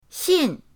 xin4.mp3